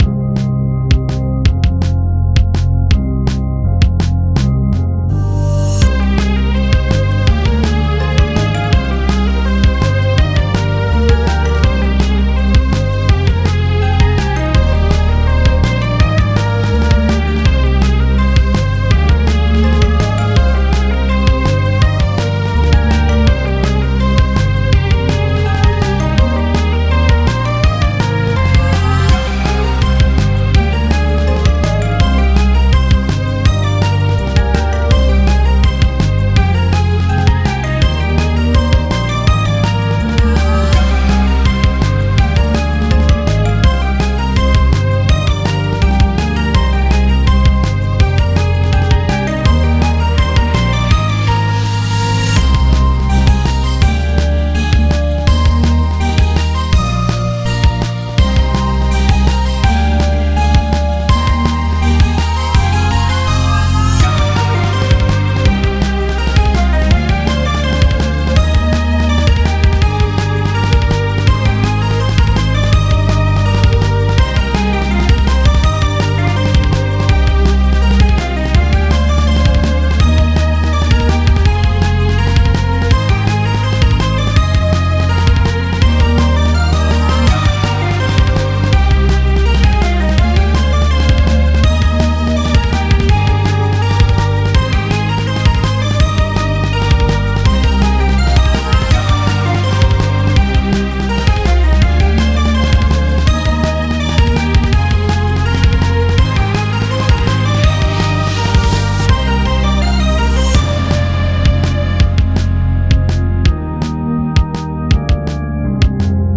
Battle music with wizard theme. This music is loopable.